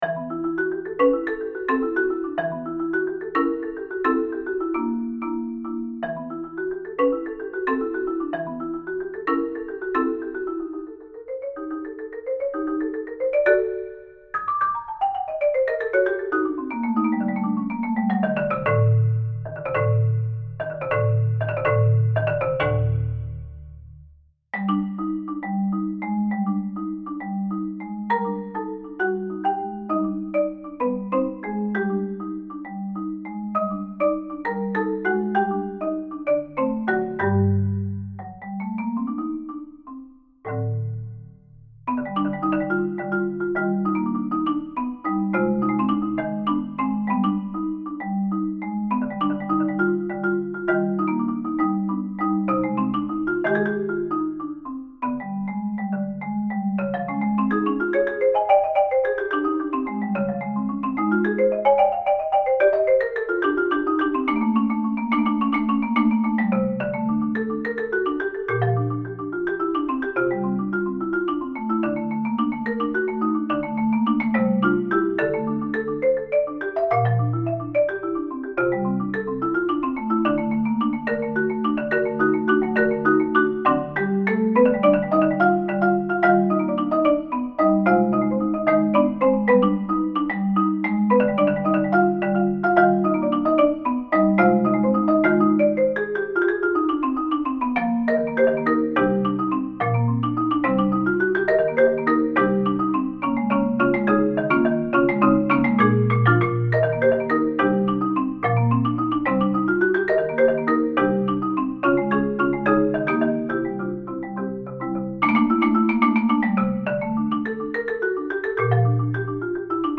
Voicing: Marimba